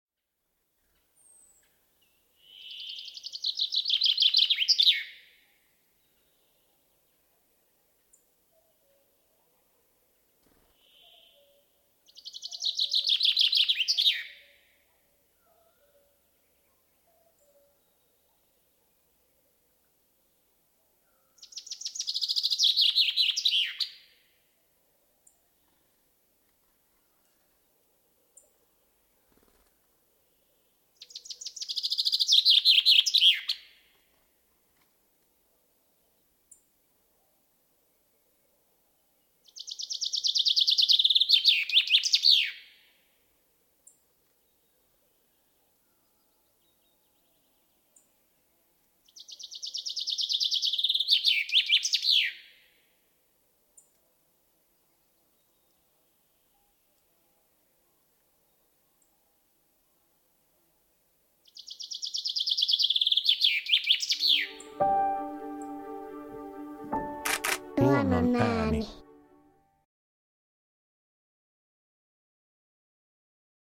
Kun peipon tuttu, iloinen säe kuuluu ensi kerran pihapuusta, monen sydämessä läikähtää.